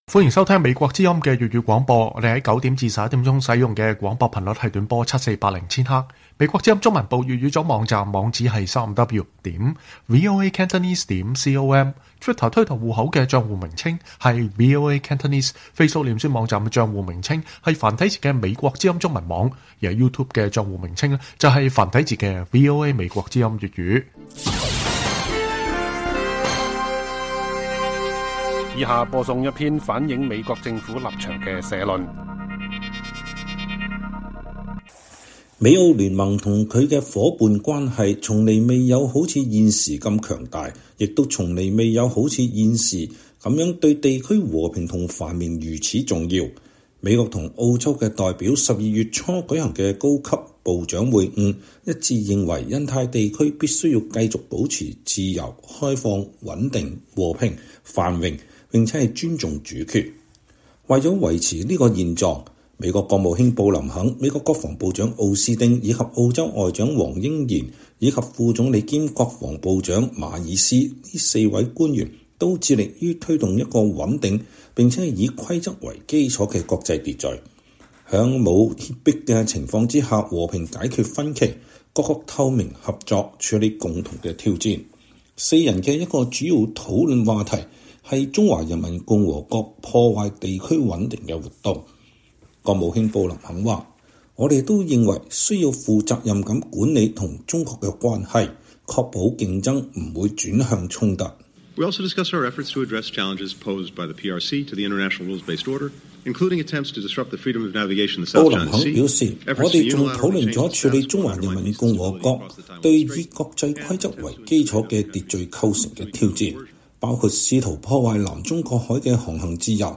美國政府政策立場社論；美澳努力推動印太地區的穩定與繁榮